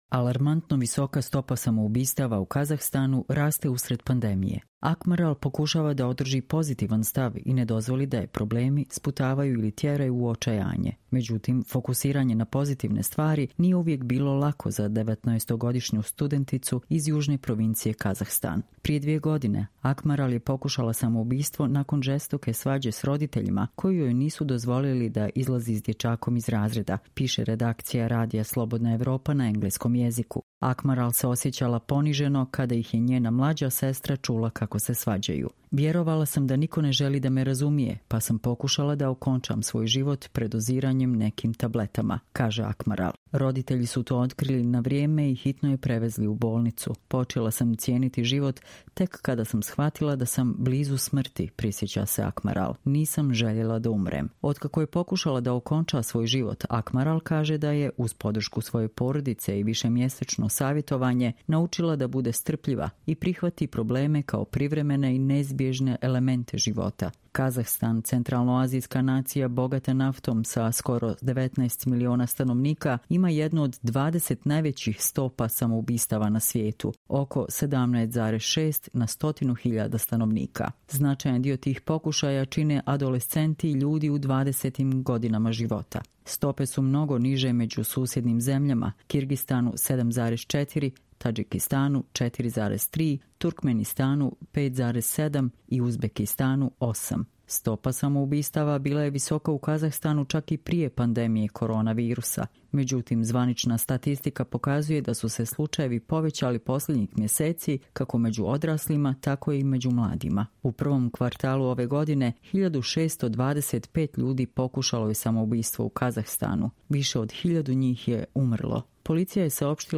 Čitamo vam: Alarmantno visoka stopa samoubistava u Kazahstanu raste usred pandemije